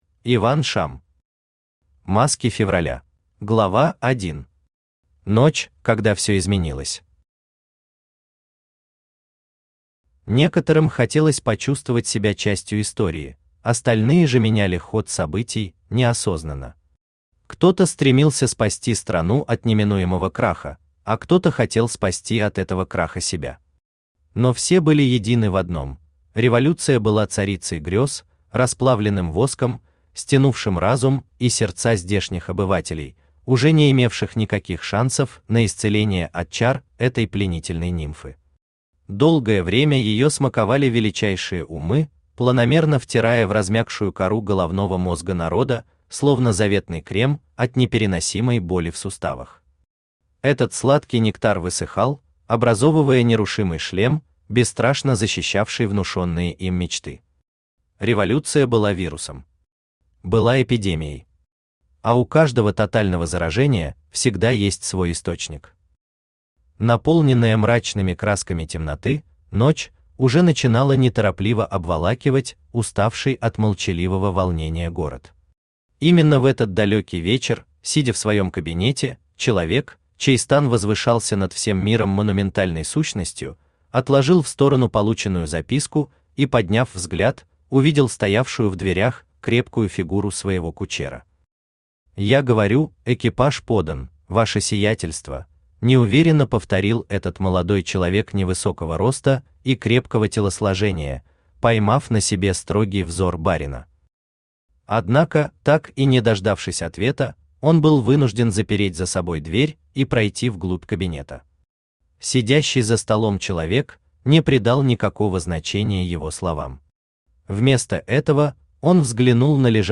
Аудиокнига Неразрешимые противоречия | Библиотека аудиокниг
Aудиокнига Неразрешимые противоречия Автор Иван Шам Читает аудиокнигу Авточтец ЛитРес.